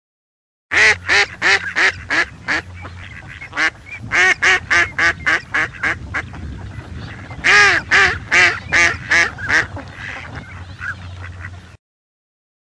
Звуки уток: кряканье кряквы